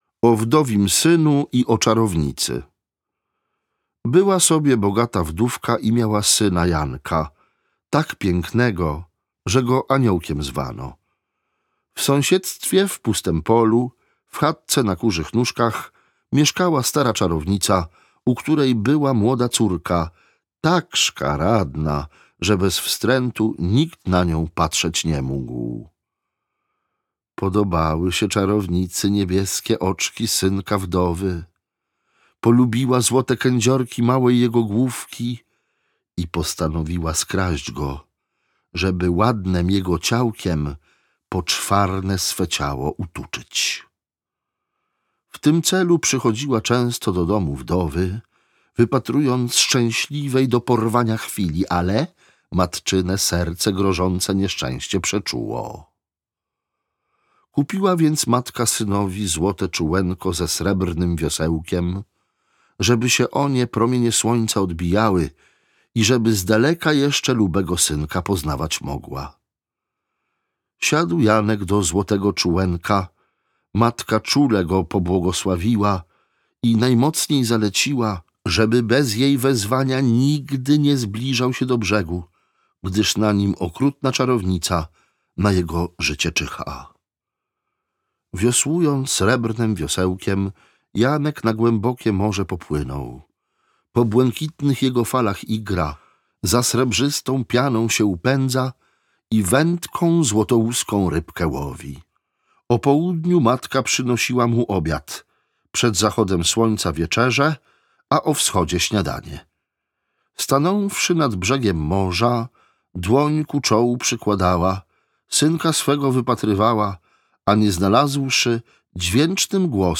Bajarz polski - Antoni Józef Gliński - audiobook